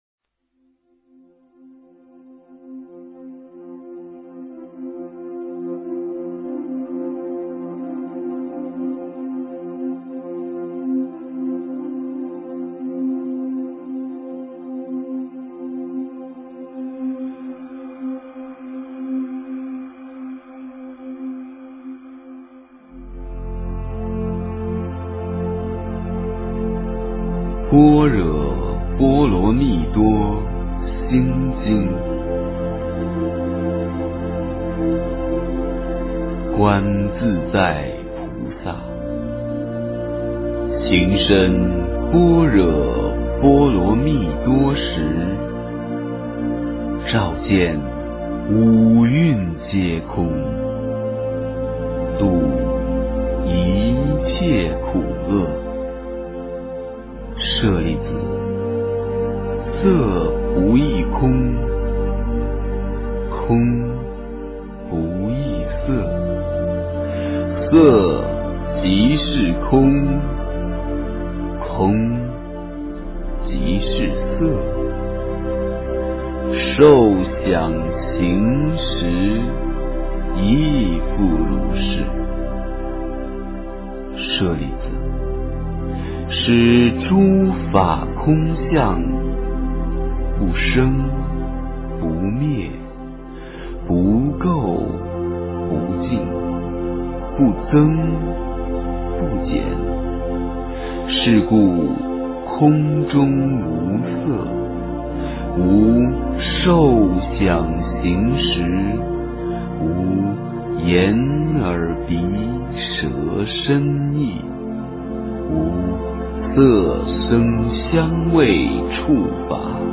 心经-念诵
诵经
佛音 诵经 佛教音乐 返回列表 上一篇： 心经 下一篇： 大悲咒 相关文章 般若菠萝蜜之无上佛法--佚名 般若菠萝蜜之无上佛法--佚名...